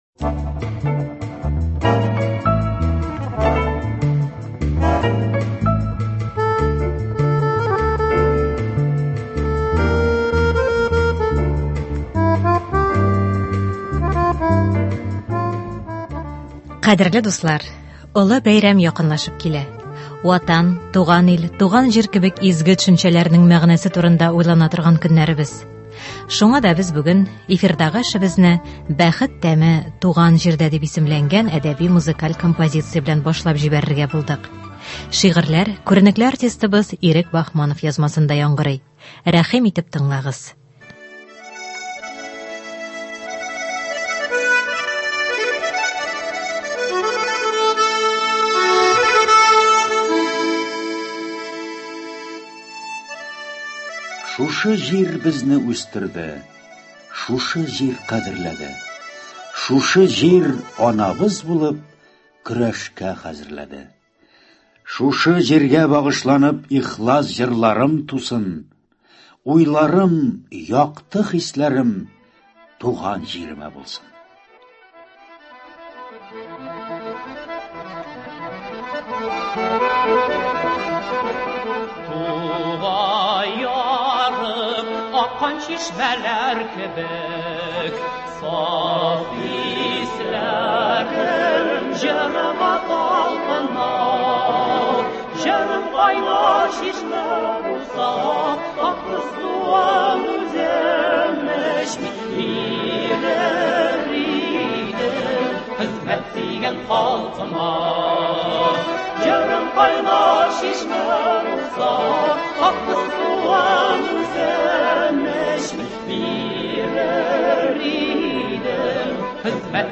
Ватан, туган ил, туган җир кебек изге төшенчәләрнең мәгънәсе турында уйлана торган көннәребез. Шуңа да без бүген эфирдагы эшебезне «Бәхет тәме – туган җирдә» дип исемләнгән әдәби-музыкаль композиция белән башлап җибәрергә булдык.